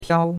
piao1.mp3